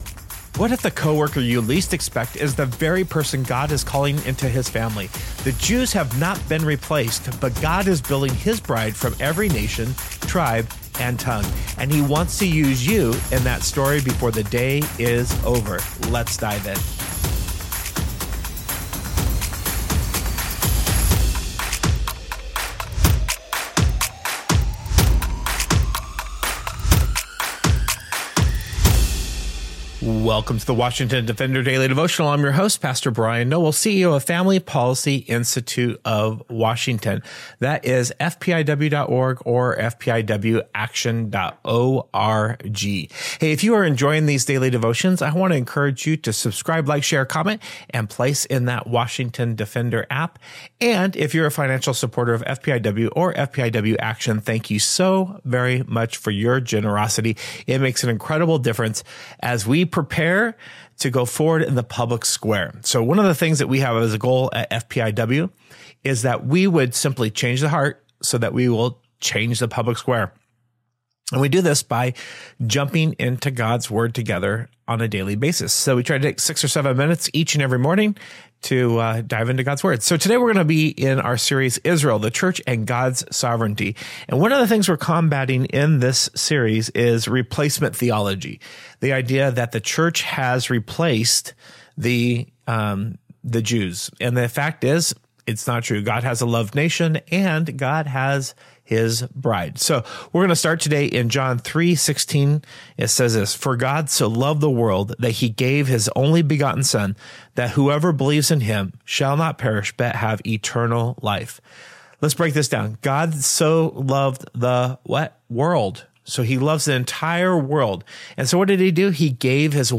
A Devotion for your drive into work: